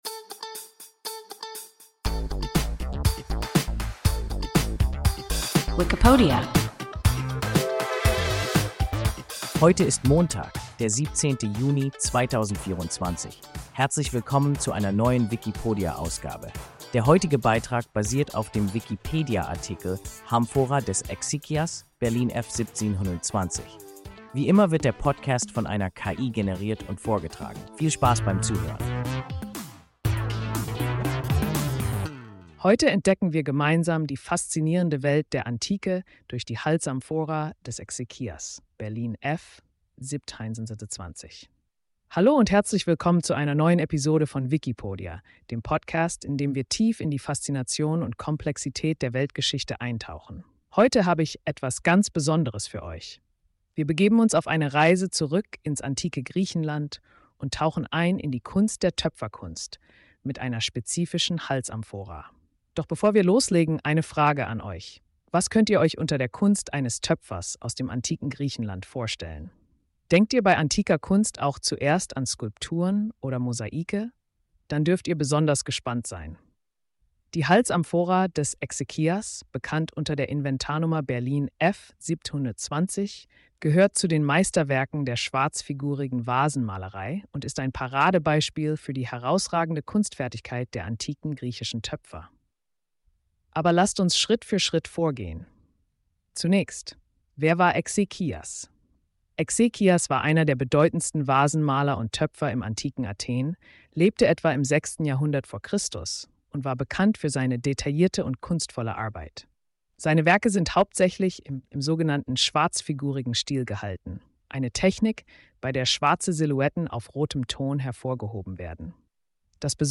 Halsamphora des Exekias (Berlin F 1720) – WIKIPODIA – ein KI Podcast